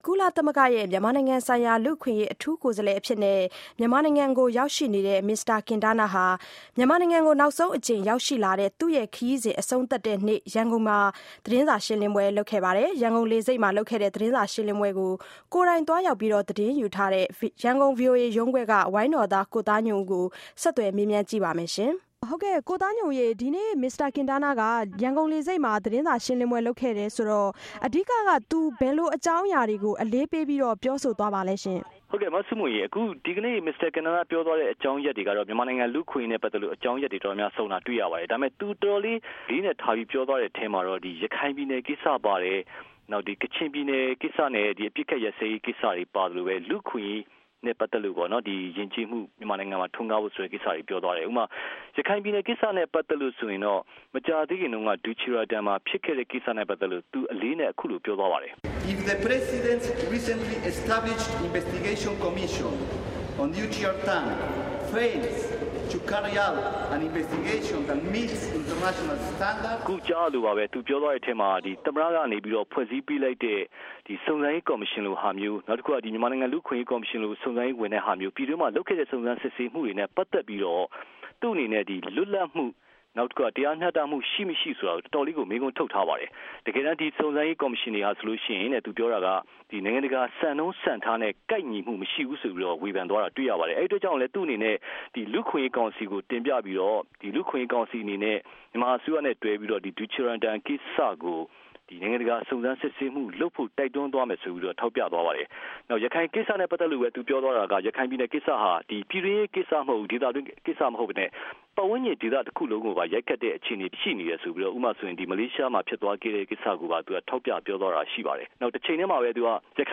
ကင်တားနား သတင်းစာရှင်းလင်းပွဲ